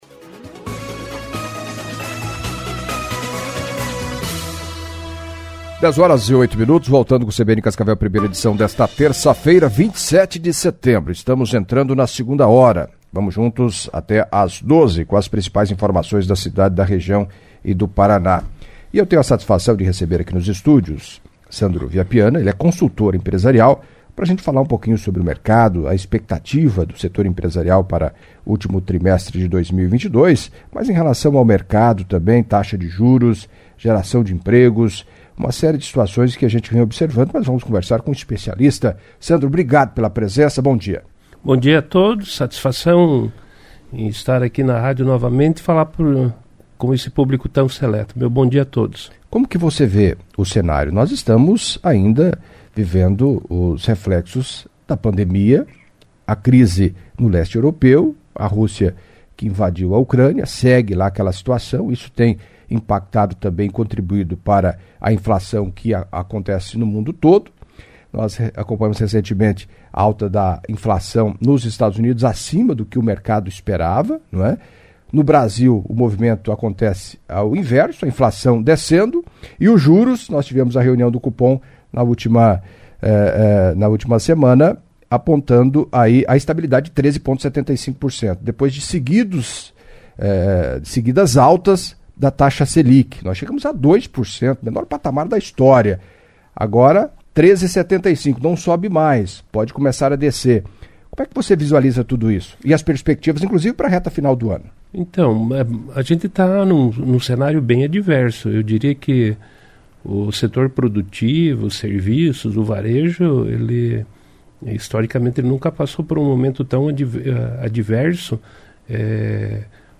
Em entrevista à CBN Cascavel nesta terça-feira (27)